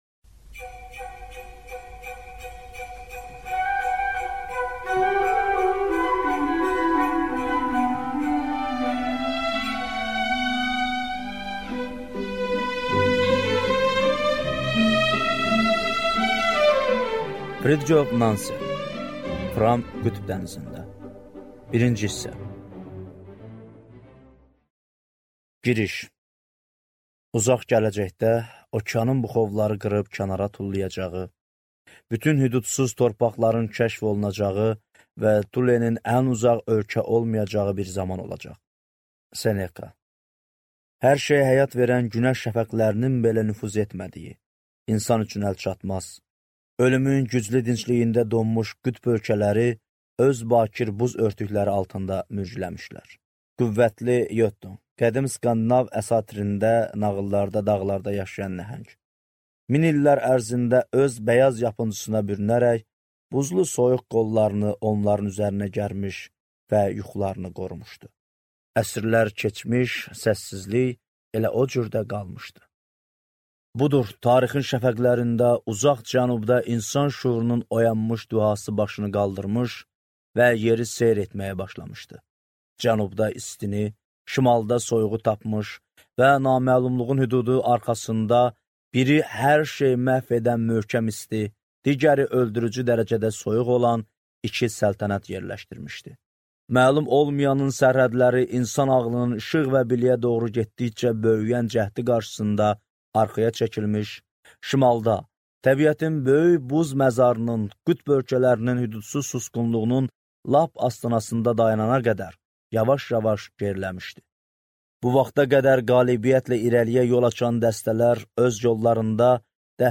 Аудиокнига Fram qütb dənizində | Библиотека аудиокниг